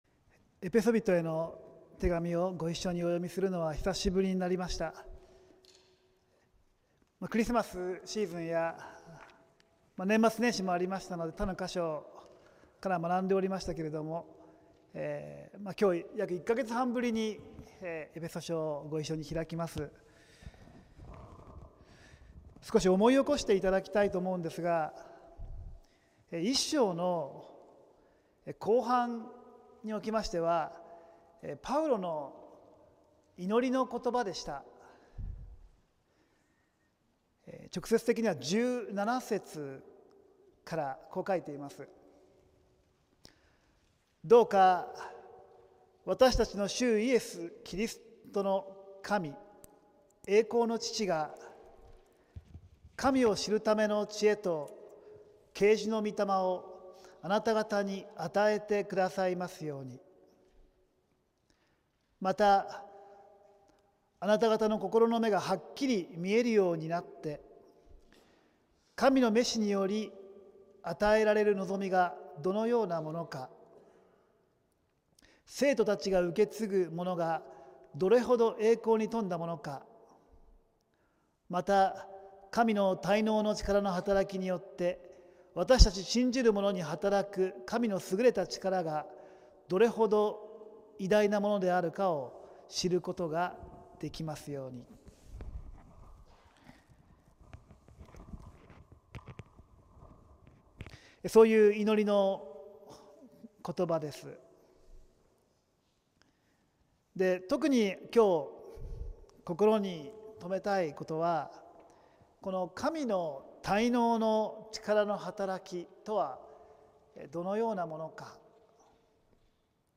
浦和福音自由教会(さいたま市浦和区)の聖日礼拝(2026年1月18日)「死からいのちへ」(週報とライブ/動画/音声配信)